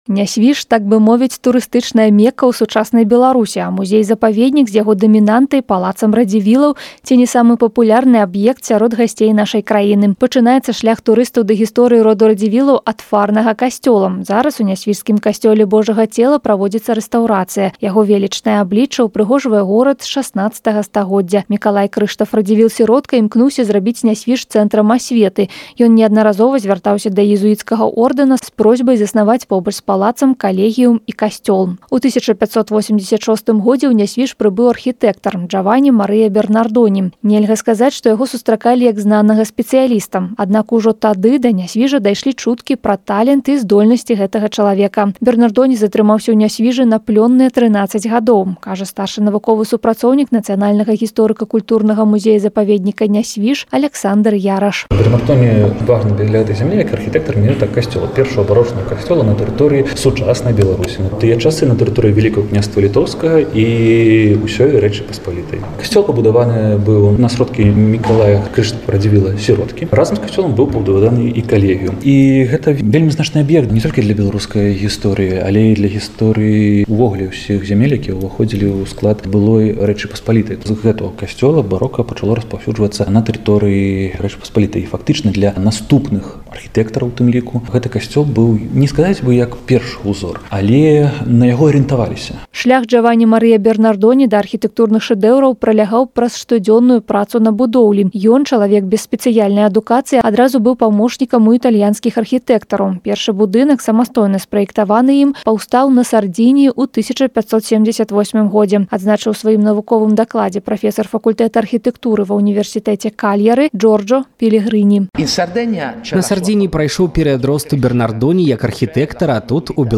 Мажорнай нотай першага беларуска-сардзінскага праекта, прысвечанага Джавані Марыі Бернардоні, стала выступленне творчага калектыву “Ценорэс Su Cunsonu Santu Juanne de Thiesi”.
Сцены Нясвіжскага замка пачулі старадаўні сардзінскі спеў, папулярны ў 16 стагоддзі, – той спеў, які, імаверна, слухаў знакаміты беларуска-сардзінскі, польскі, сусветны архітэктар Бернардоні.